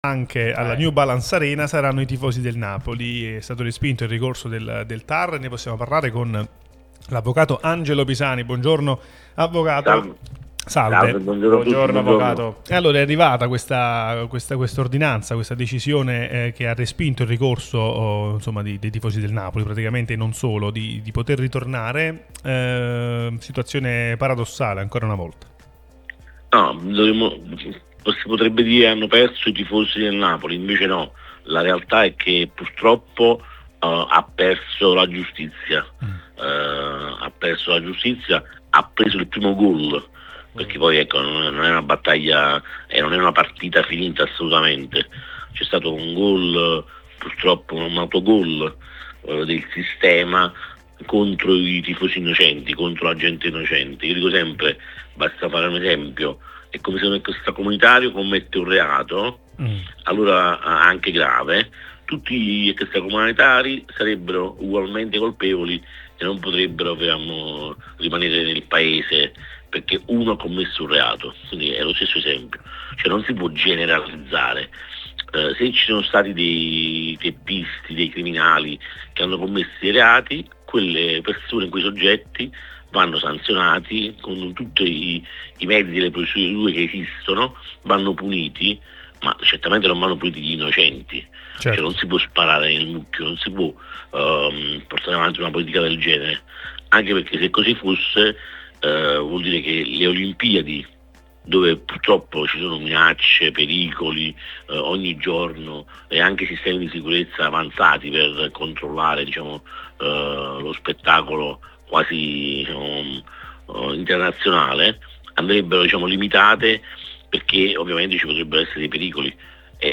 durante il Bar di Tutto Napoli, è intervenuto su Radio Tutto Napoli